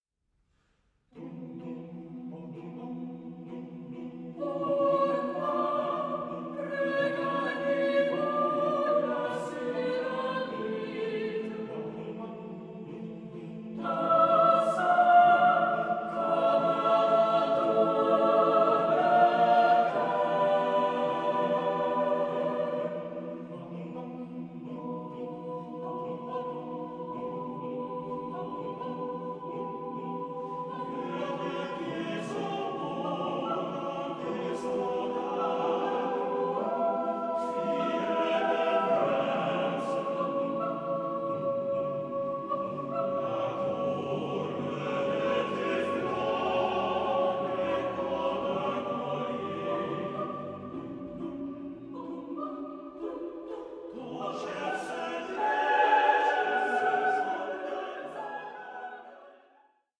SACRED CHORAL MASTERWORKS